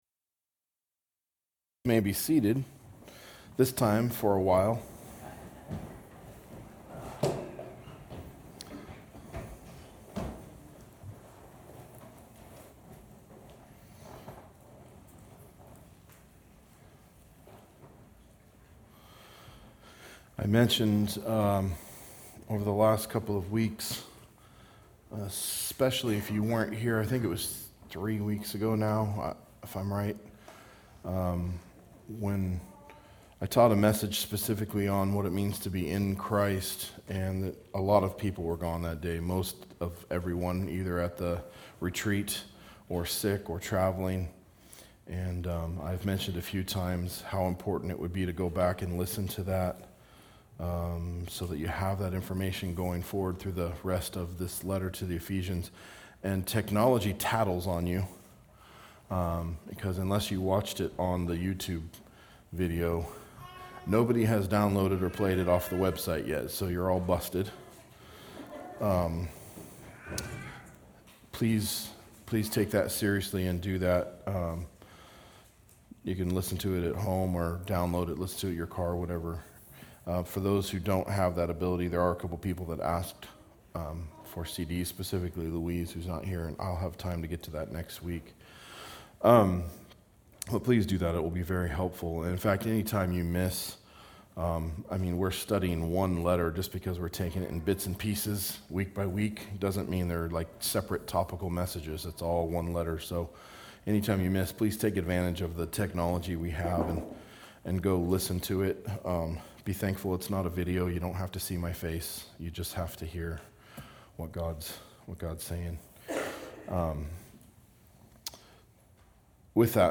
A message from the series "Ephesians." Exposition of Ephesians 1:7-12